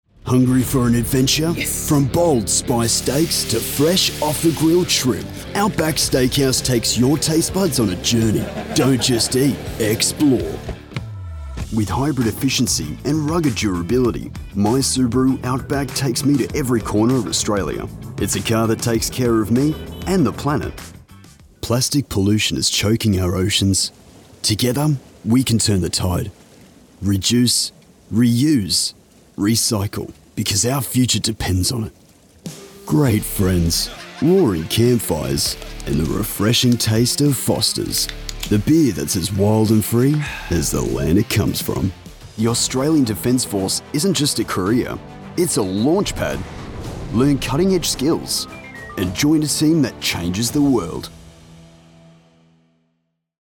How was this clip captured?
Browse our catalog of professional voice actor demos recorded, mixed, and produced in-house at Edge Studio NYC.